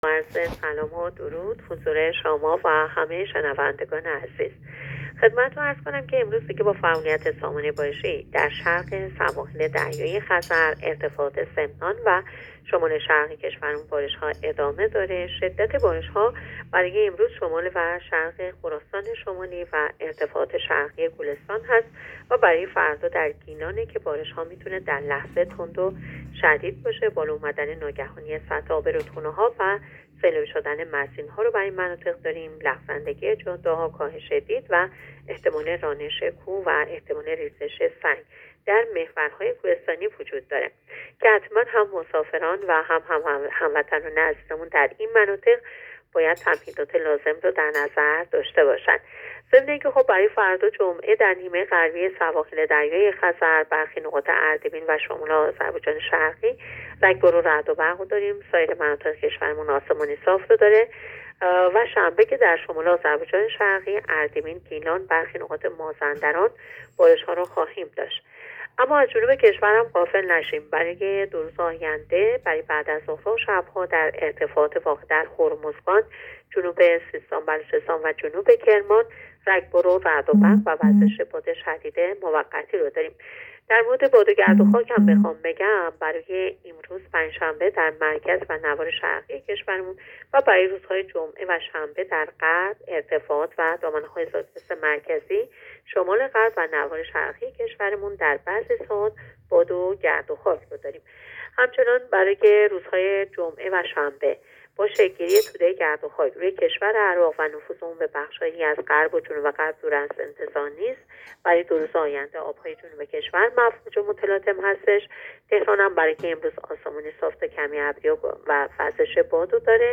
گزارش رادیو اینترنتی پایگاه‌ خبری از آخرین وضعیت آب‌وهوای سوم مهر؛